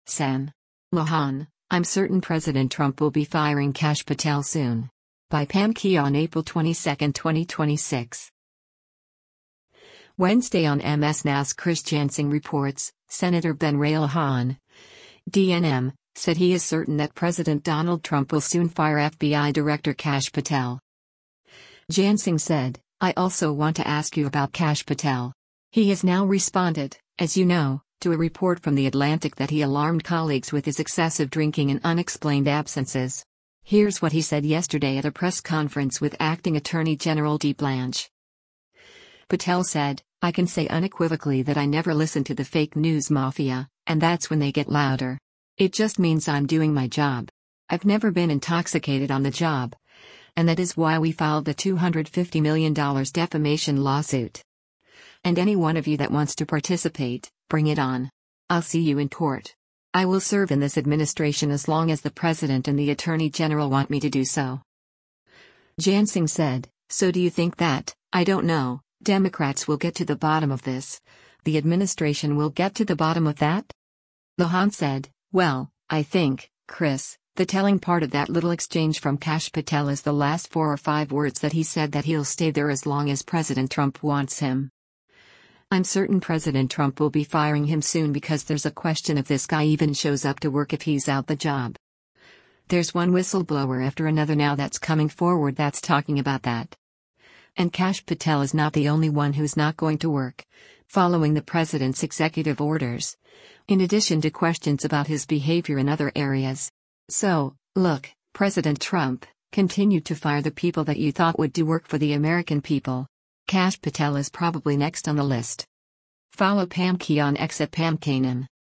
Wednesday on MS NOW’s “Chris Jansing Reports,” Sen. Ben Ray Luján (D-NM) said he is “certain” that President Donald Trump will soon fire FBI Director Kash Patel.